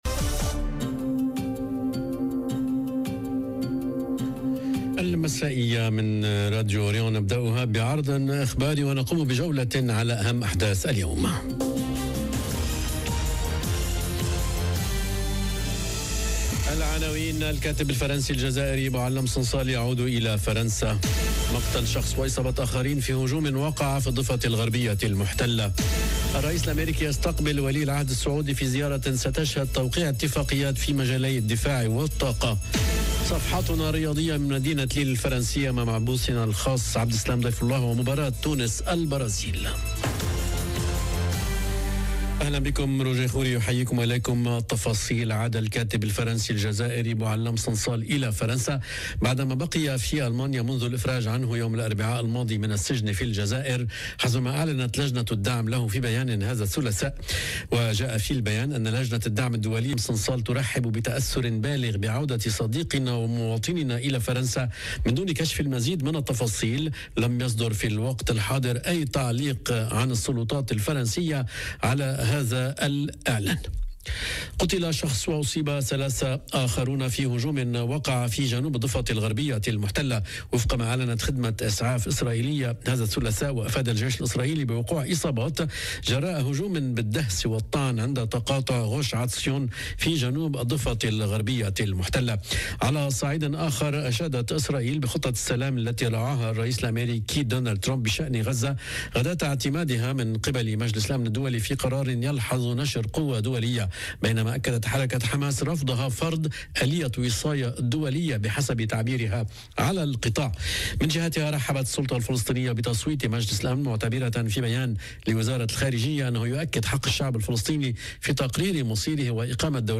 نشرة أخبار المساء: صنصال يعود إلى فرنسا، ومقتل شخص وإصابة آخرين في الضفة الغربية، والرئيس الأمريكي يستقبل ولي العهد السعودي - Radio ORIENT، إذاعة الشرق من باريس